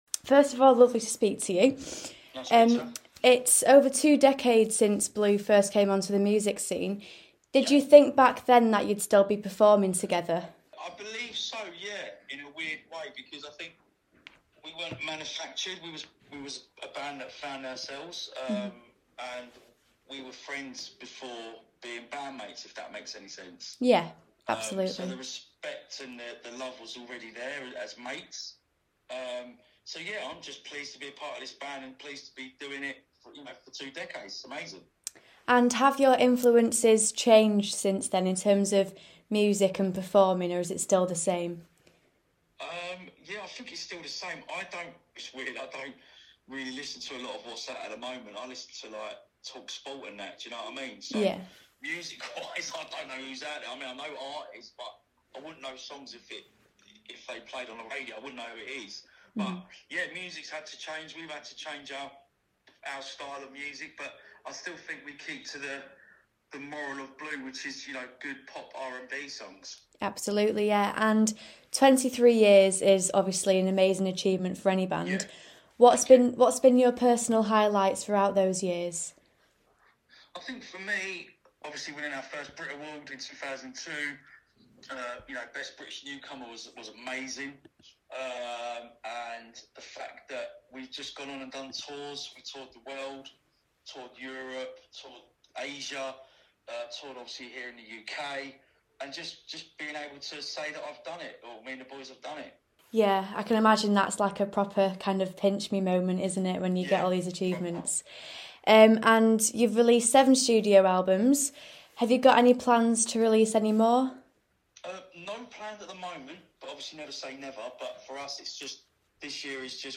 INTERVIEW : Blue's Anthony Costa about his career and upcoming festivals